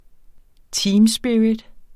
Udtale [ ˈtiːmˌsbiɹit ]